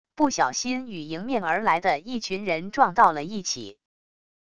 不小心与迎面而来的一群人撞到了一起wav音频